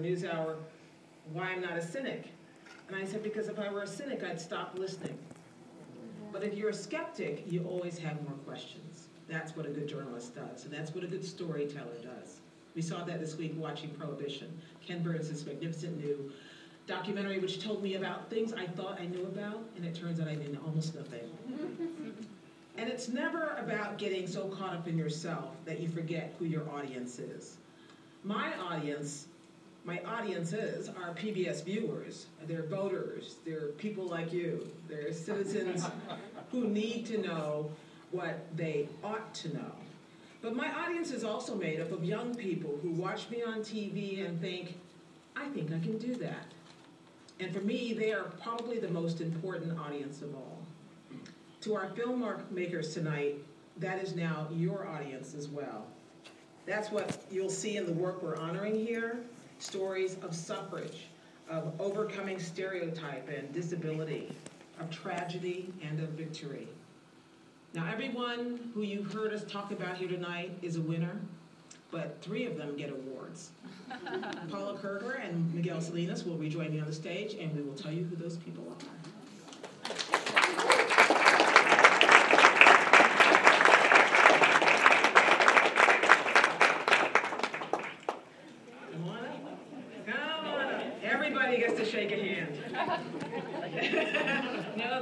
Gwen Ifill from PBS speaks prior to ProjectVoiceScape awards in DC.